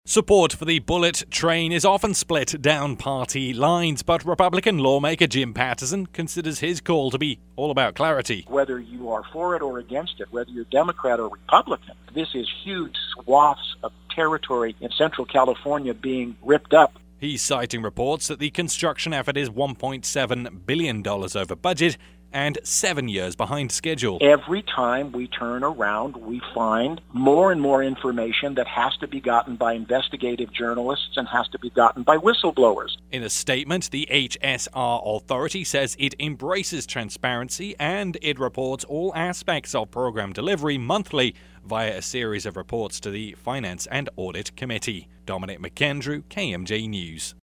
as it aired